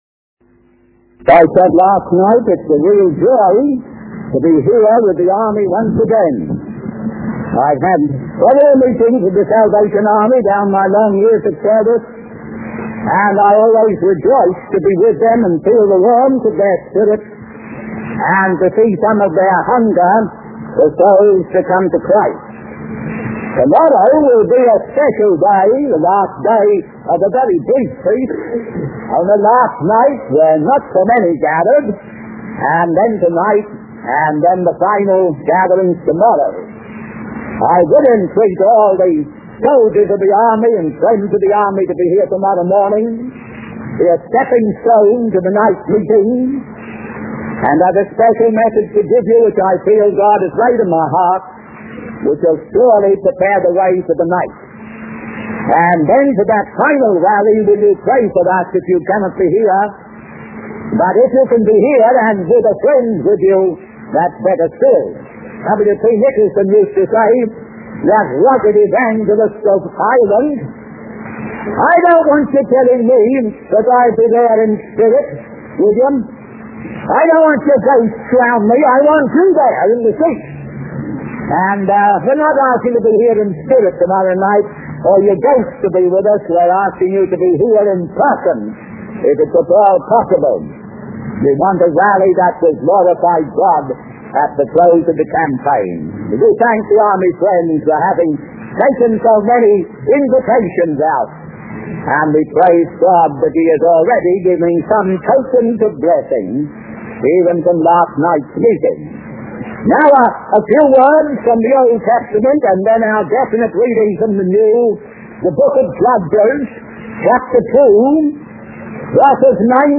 In this sermon, the speaker emphasizes the urgency of responding to the call of Jesus. He describes the current state of the world as filled with silence, strangeness, and terrible conditions.